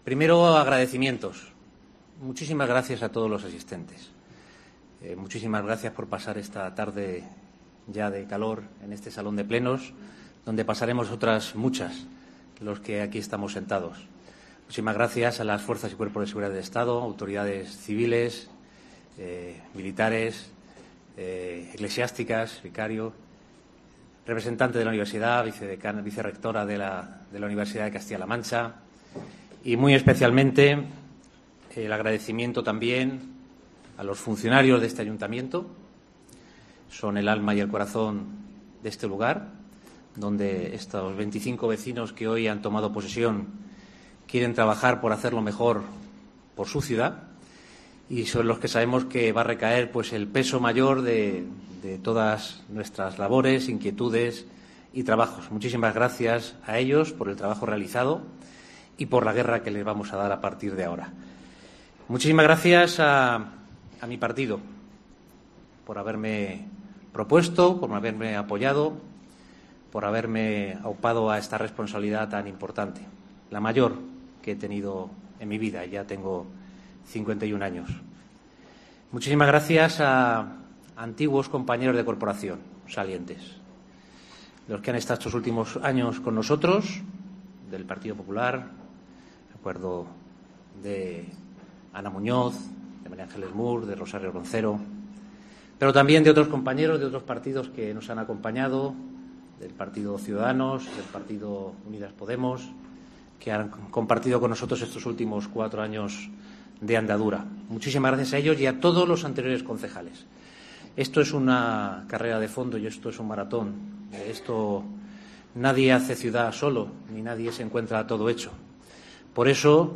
Discurso de Francisco Cañizares (PP) tras ser elegido nuevo alcalde de Ciudad Real